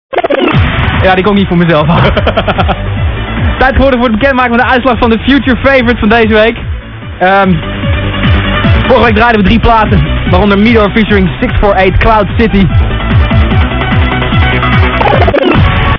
track playing in the background